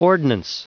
Prononciation du mot ordnance en anglais (fichier audio)
Prononciation du mot : ordnance